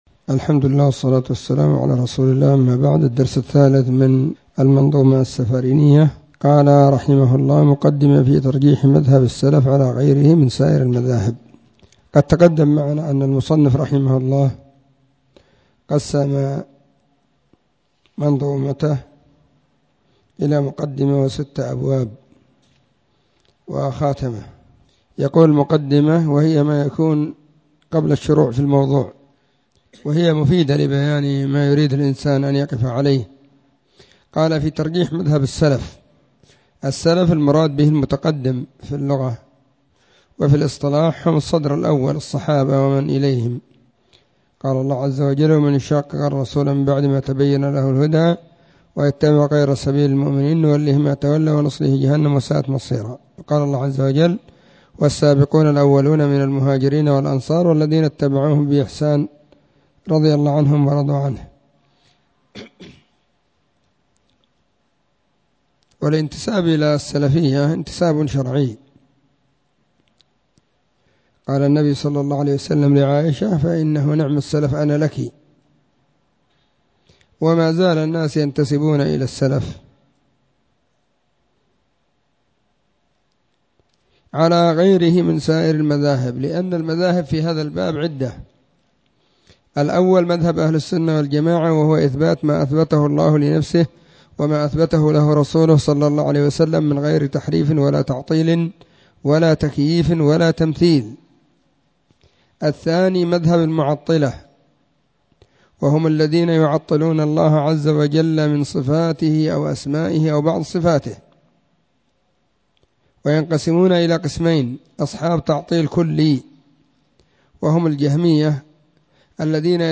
📢 مسجد الصحابة بالغيضة, المهرة، اليمن حرسها الله.
العقيدة-السفارينية-الدرس-3.mp3